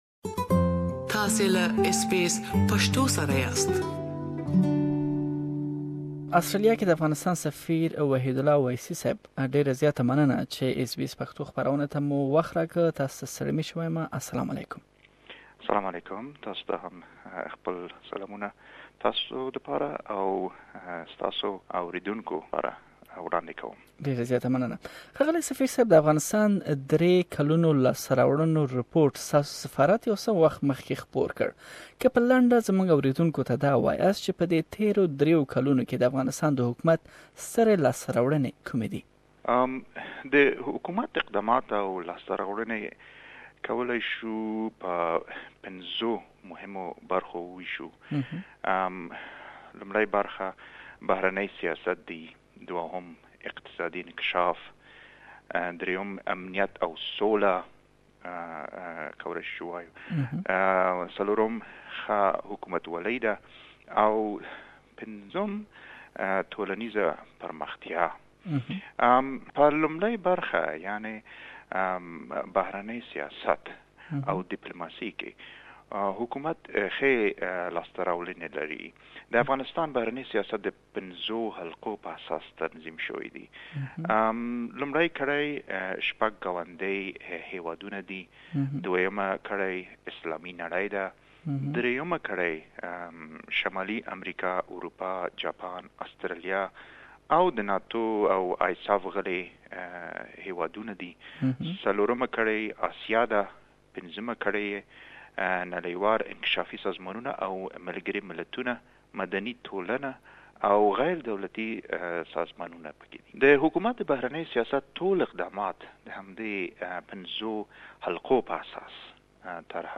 Exclusive interview with Afghan Ambassador to Australia
SBS Pashto program has interviewed Afghan Ambassador exclusively to Australia H.E. Wahidullah Waisi on a range of issues.